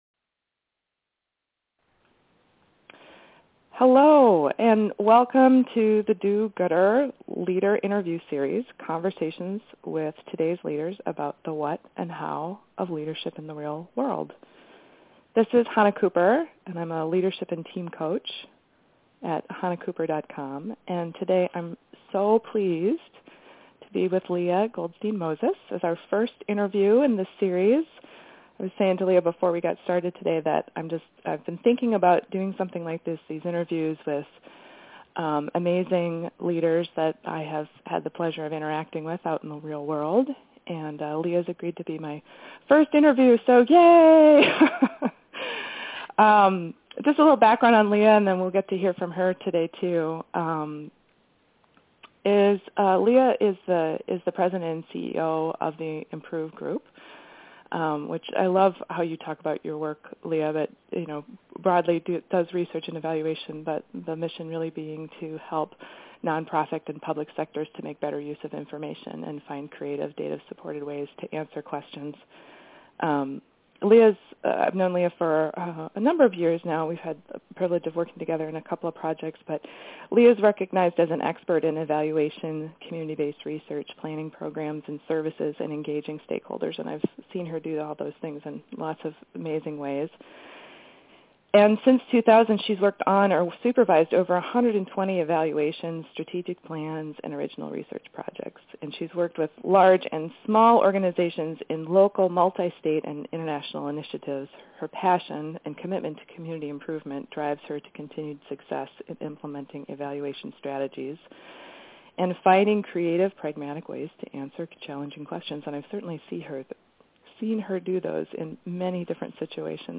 Based on these concepts, I’m thrilled to be introducing a new monthly feature starting today – interviews with do-gooder leaders. In these interviews, you’ll hear about the experiences of real world leaders who make a difference: what their path has been to leadership, what they’ve learned , what’s helped them succeed, what they’d do differently if they were starting out.